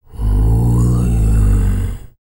TUVANGROAN07.wav